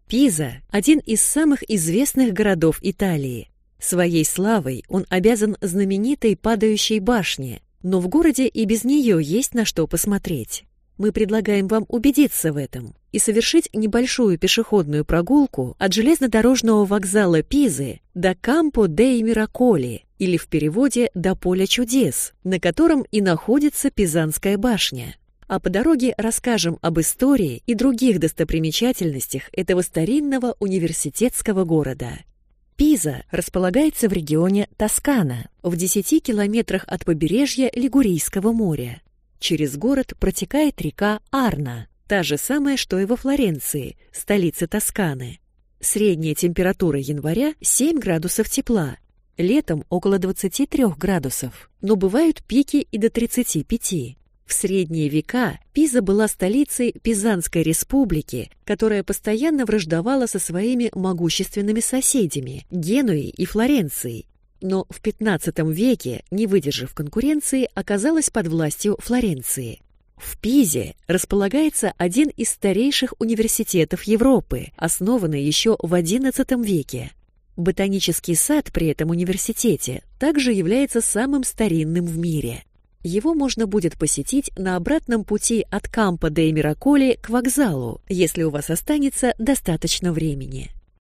Аудиокнига Пиза. Аудиогид | Библиотека аудиокниг